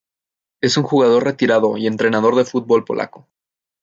po‧la‧co
/poˈlako/